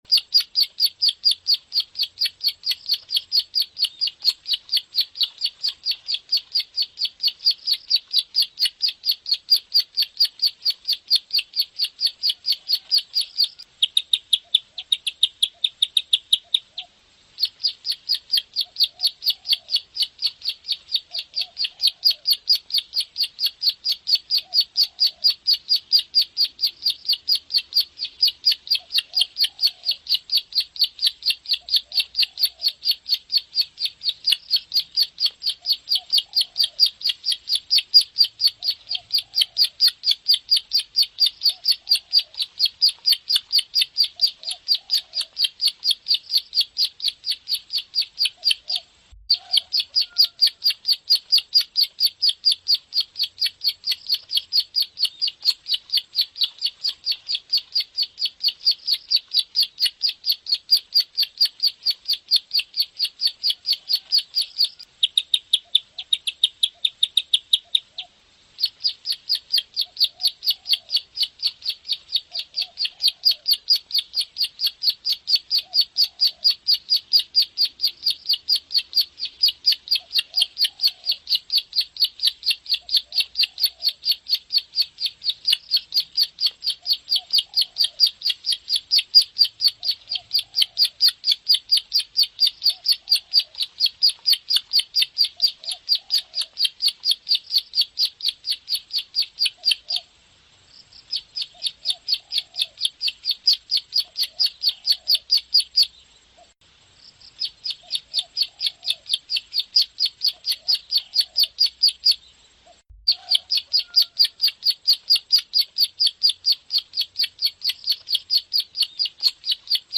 Mp3 Suara Burung Prenjak Jantan [Masteran]
> Suara Burung Prenjak Lumut Ngebren